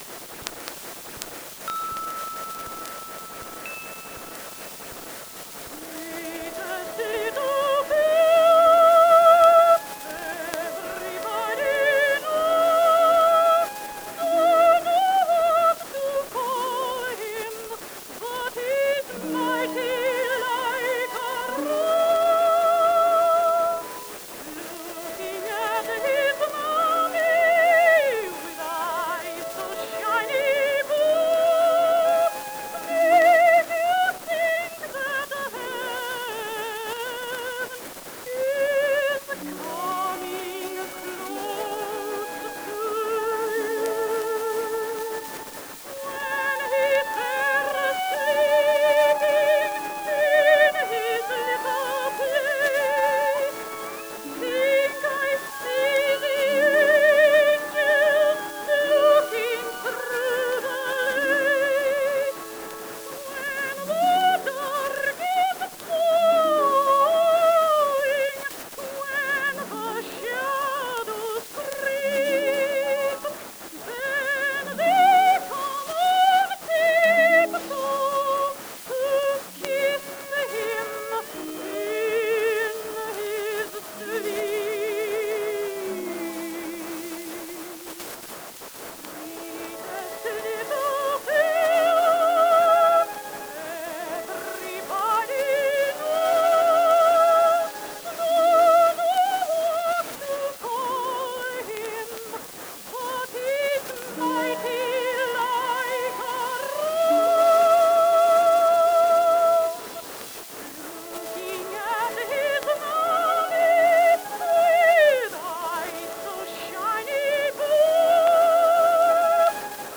IRENE-scan of Edison Master Mold 4897-C -- audio not restored (96 kHz / 32 bit)
Filename: 4897-C_Copper_MAS.wav. Audio not restored (96 kHz sample rate ; 32 bit float depth ; mono). Historic recording: "Mighty lak' a rose" performed by Anna Case ; recorded in New York, New York by Thomas A. Edison, Incorporated on July 17, 1916.